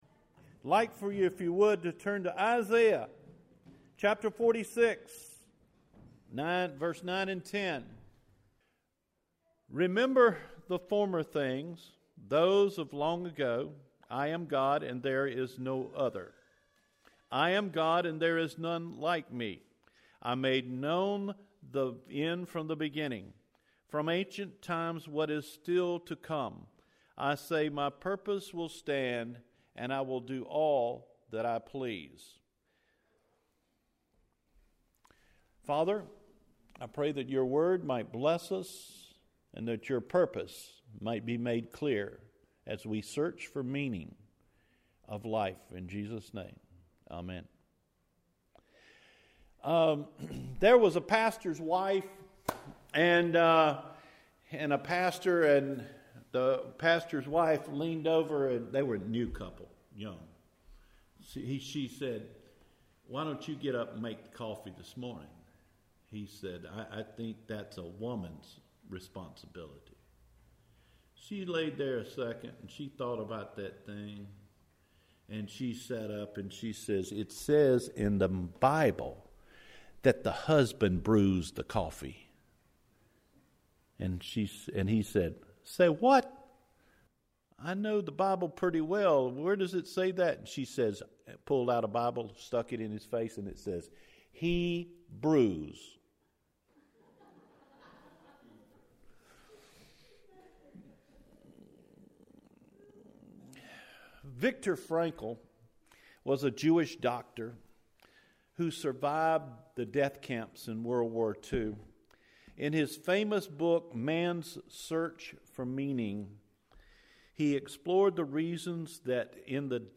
Meaning of Life – April 29 Sermon – Cedar Fork Baptist Church